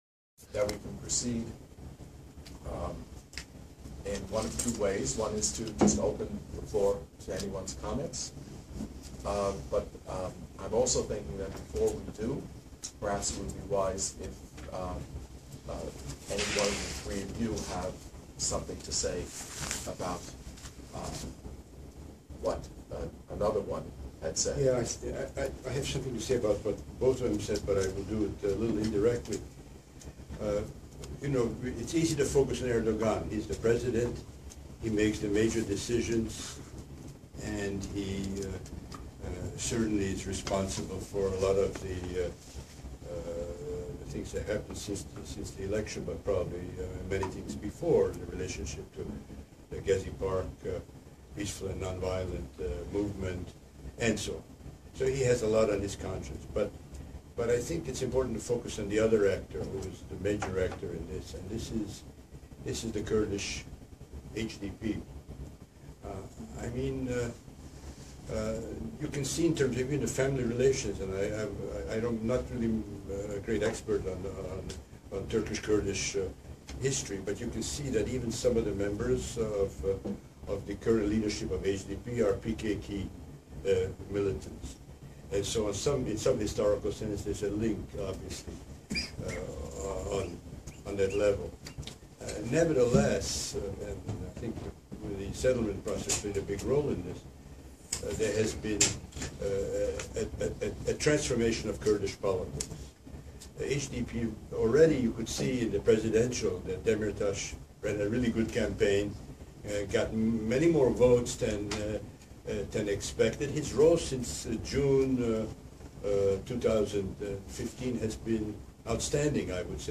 The event was broadcasted live on our Periscope account.
Intensifying-Criris-in-Turkey-Discussion.mp3